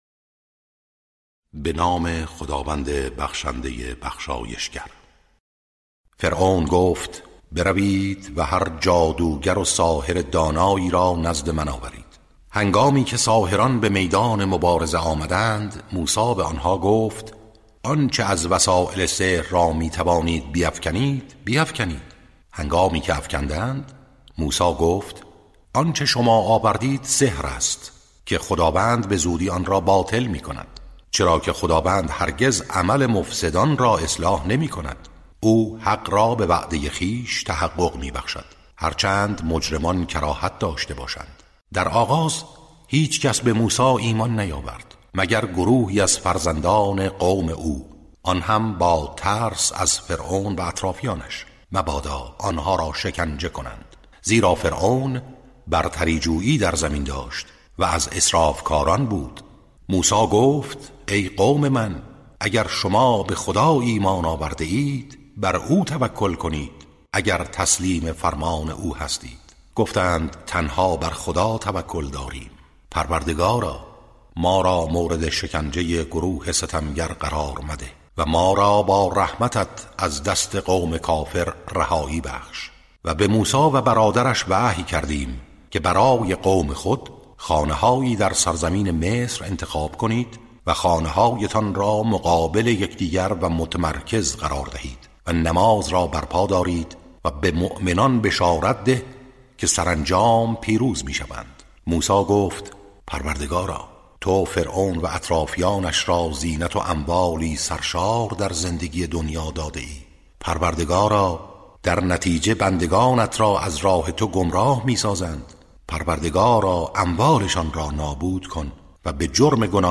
ترتیل صفحه ۲۱۸ سوره مبارکه یونس(جزء یازدهم)